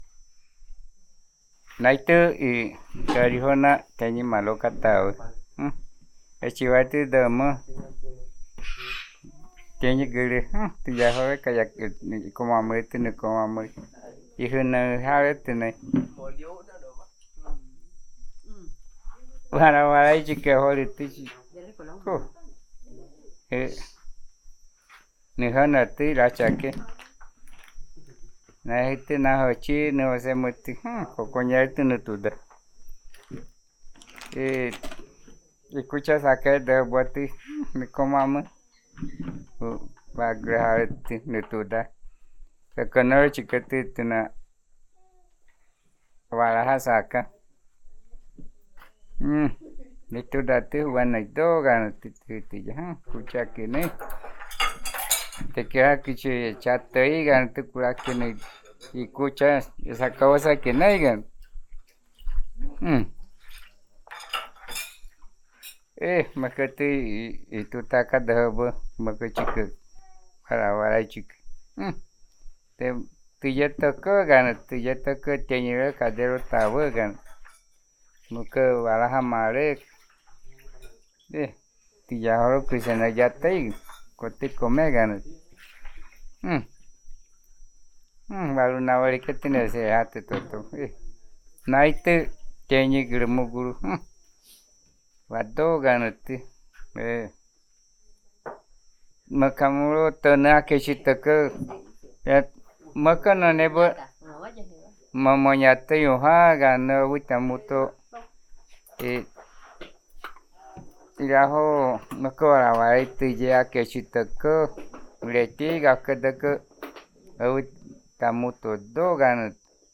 Puerto Nare, Guaviare